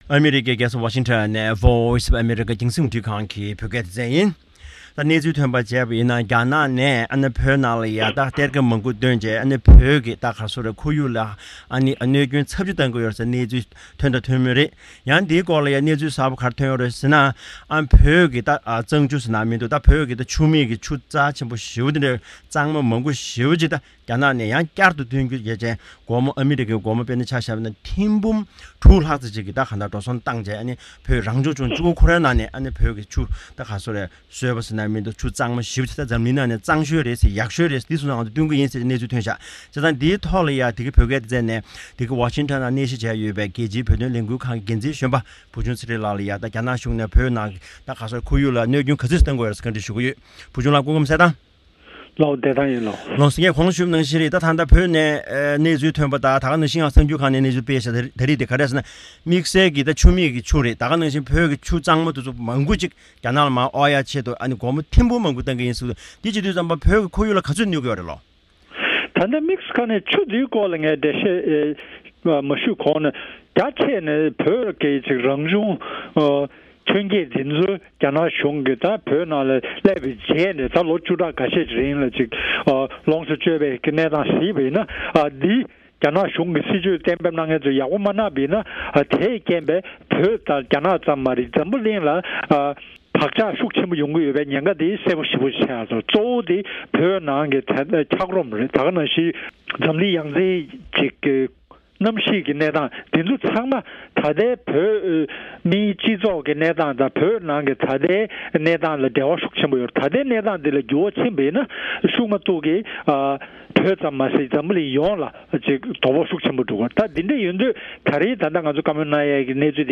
འབྲེལ་ཡོད་མི་སྣར་གནས་འདྲི་གནང་བའི་གནས་ཚུལ་དེ་གསན་རོགས་གནང་།